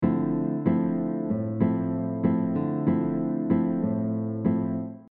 This mellow and beautiful style uses syncopated rhythms with a fingerpicking style of playing.
Alternating bossa nova rhythm - adding syncopation
Bossa-Nova-pattern-3.mp3